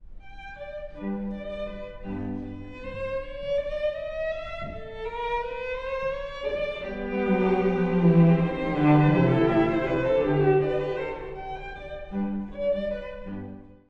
（冒頭）　※古い録音のため聴きづらいかもしれません！
第2楽章…爽やかなメヌエットと彷徨うトリオ
明るく爽やかなメヌエット、彷徨うような暗いトリオの楽章です。